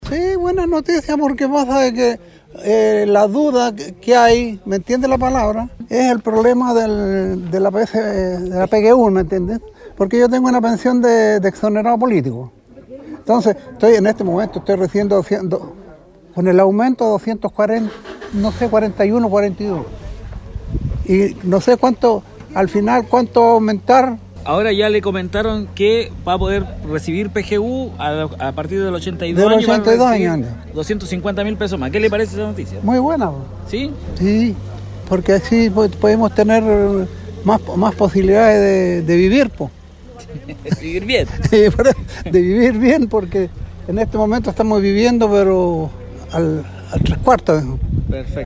Cuna-vecino.mp3